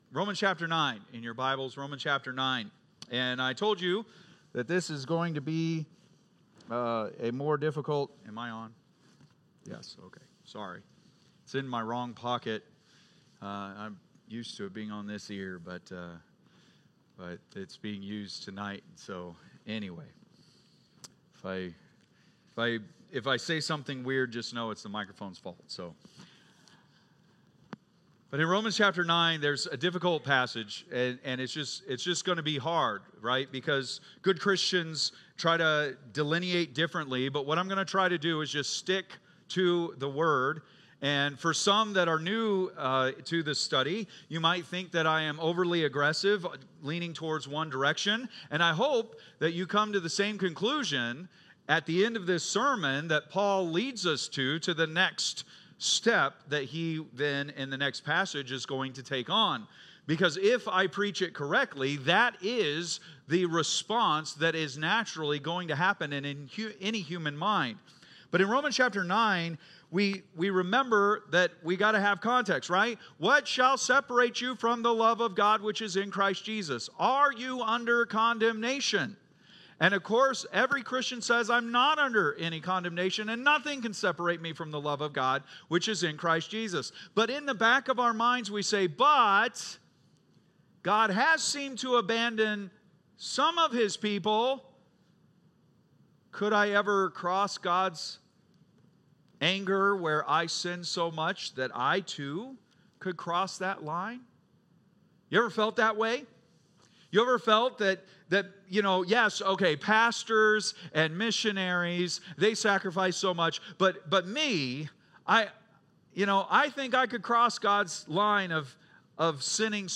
Date: December 15, 2024 (Sunday Morning)